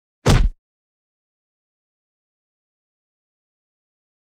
赤手空拳击中肉体-低频-YS070524.wav
通用动作/01人物/03武术动作类/空拳打斗/赤手空拳击中肉体-低频-YS070524.wav
• 声道 立體聲 (2ch)